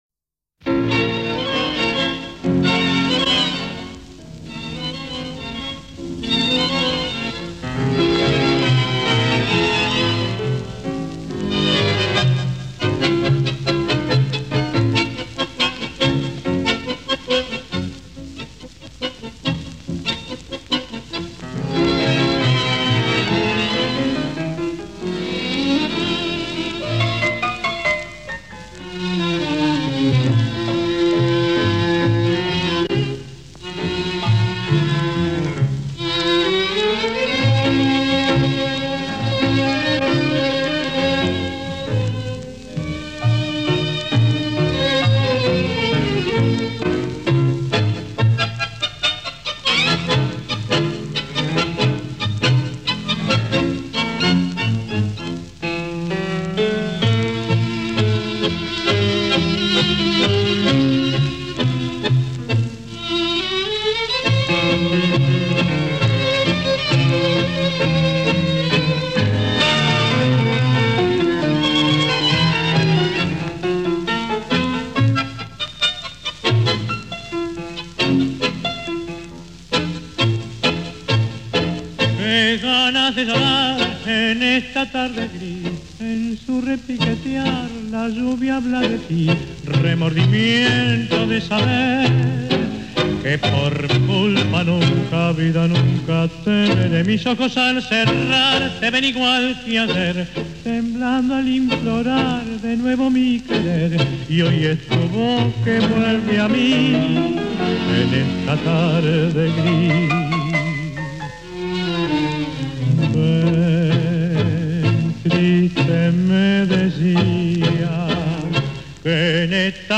Hier der Tango